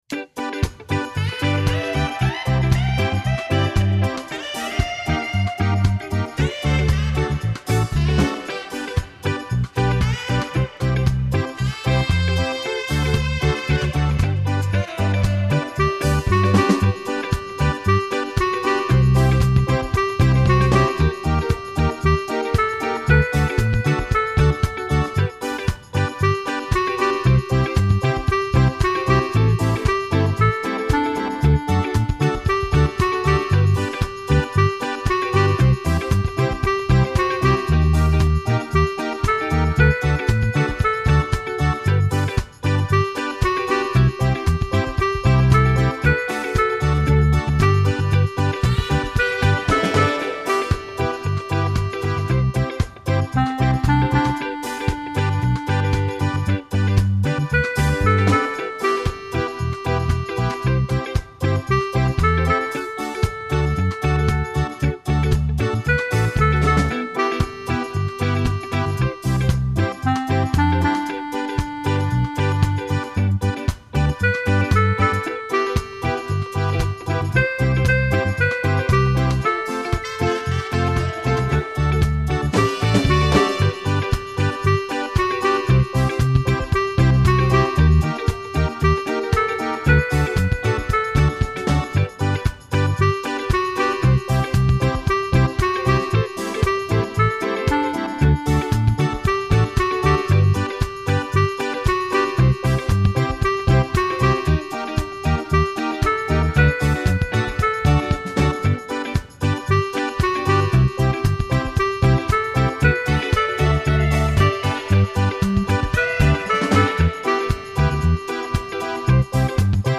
reggae recessional
My backing’s not as relaxed.